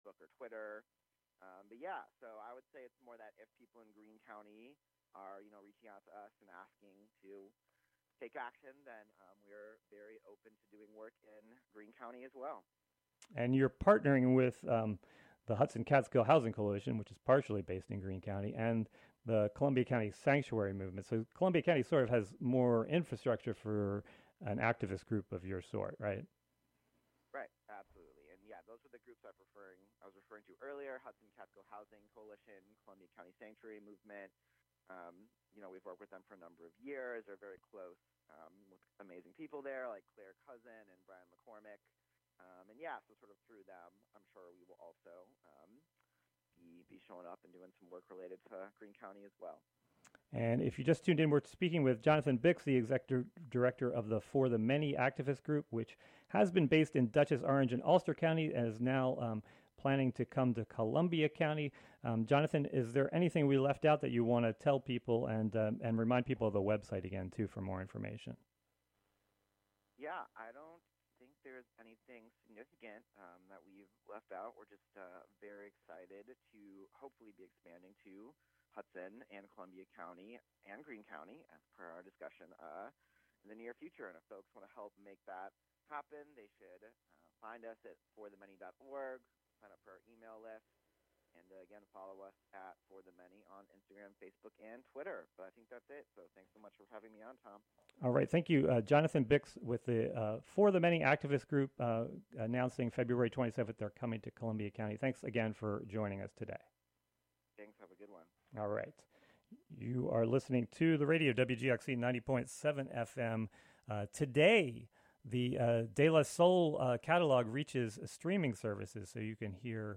Youth Radio